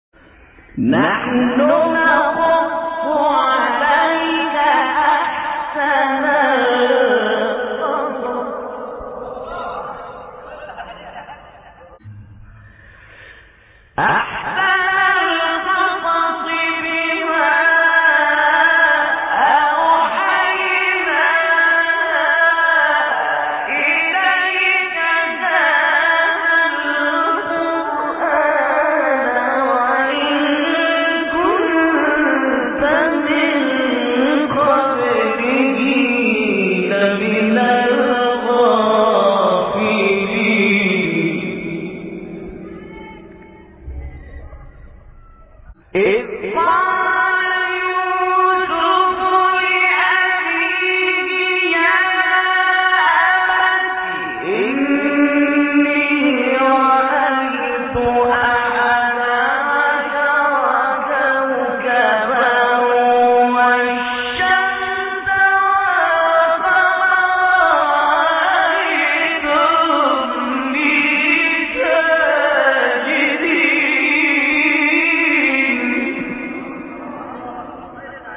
گروه فعالیت‌های قرآنی: فرازهای صوتی دلنشین با صوت قاریان برجسته مصری ارائه می‌شود.
مقطعی از سوره یوسف با صوت عبدالمنعم طوخی